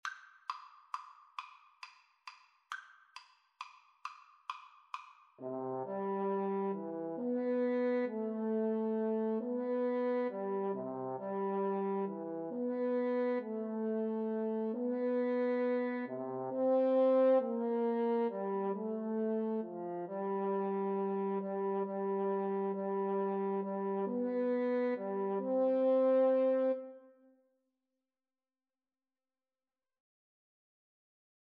Christmas
6/8 (View more 6/8 Music)
.=45 Gently Lilting .=c.45